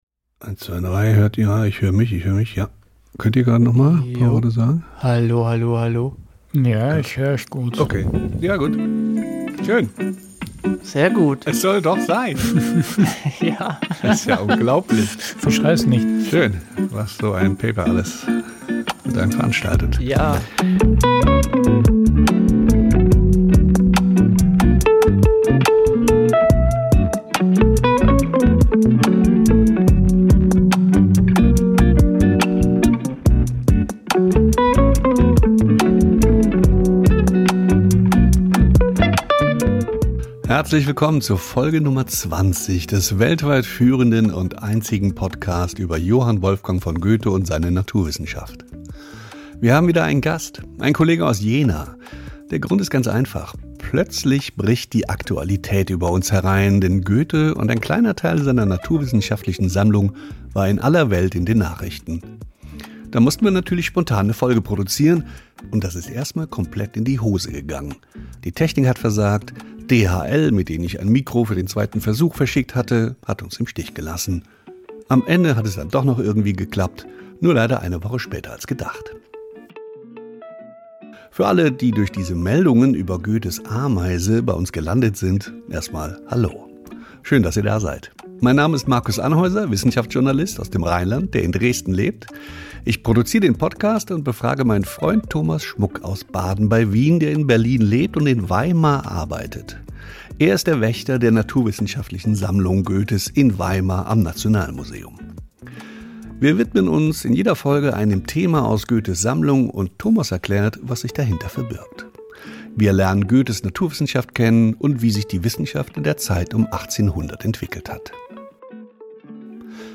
Die Folge erscheint etwas später als gedacht, weil die Drei ungewöhnlich viele Hürden zu nehmen hatten, bis die Aufnahme im Kasten war.